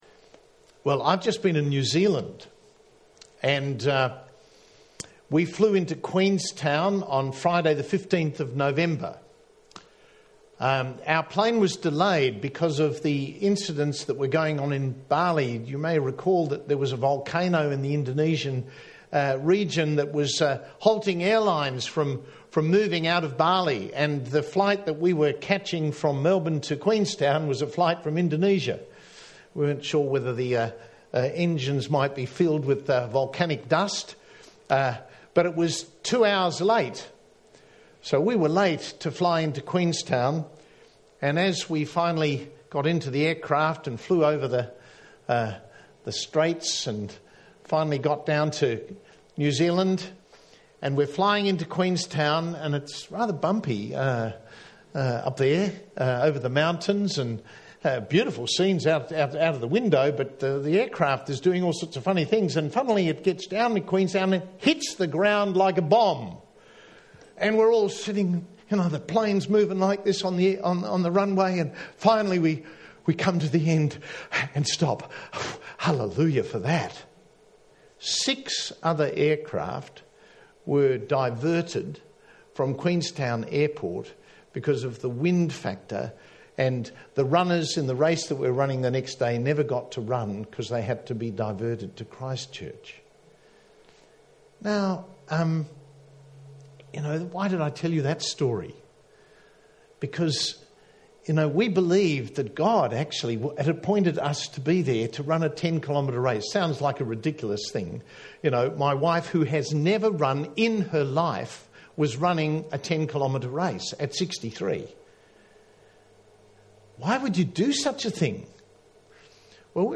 10am -The God Of Hope (Kids Hope Service) - St. Andrew's Presbyterian Church Clayton